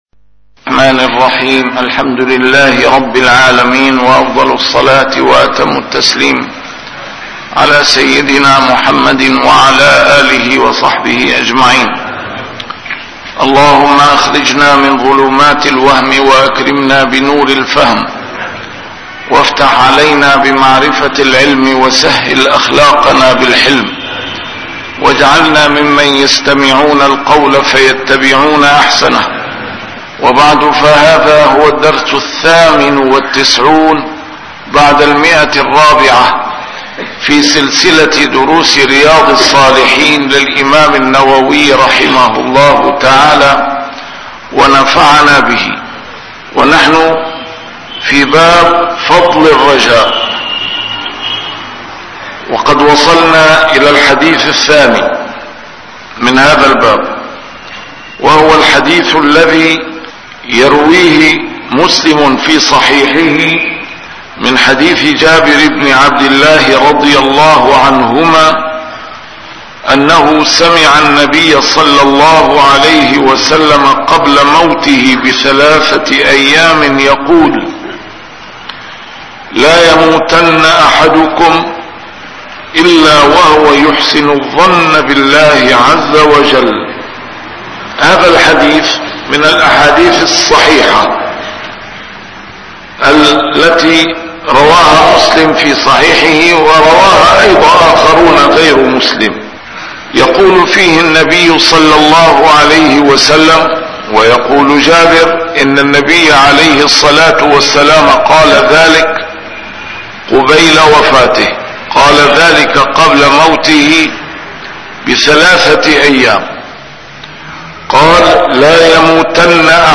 A MARTYR SCHOLAR: IMAM MUHAMMAD SAEED RAMADAN AL-BOUTI - الدروس العلمية - شرح كتاب رياض الصالحين - 498- شرح رياض الصالحين: فضل الرجاء